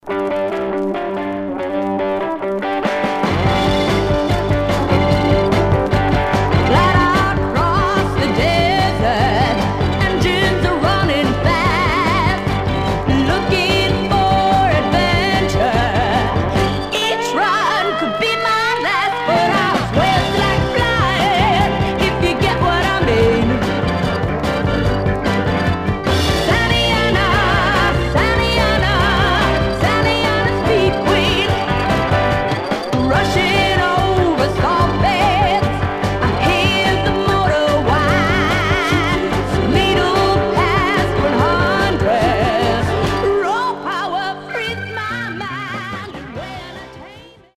Mono
Garage, 60's Punk